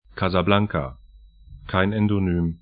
Pronunciation
Casablanca kaza'blaŋka Ad Dār al Baydā' ar Stadt / town 33°35'N, 07°30'W